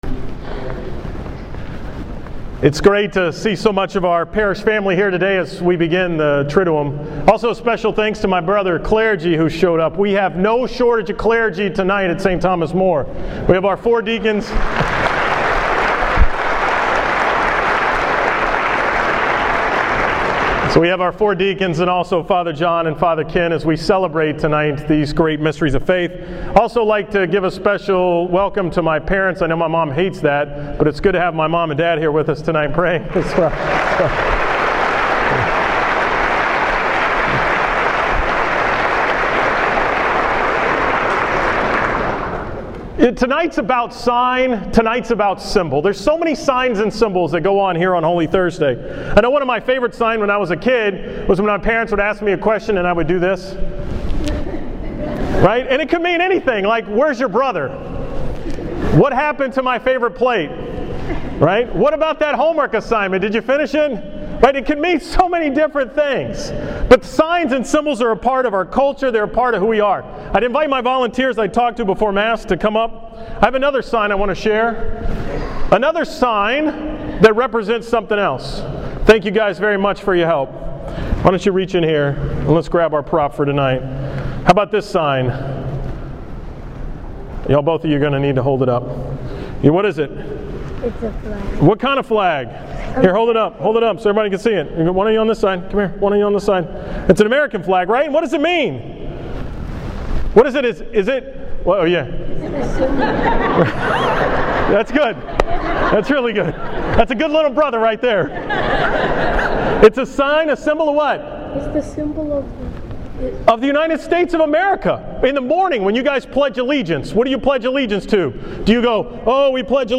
From the Holy Thursday Mass on April 17, 2014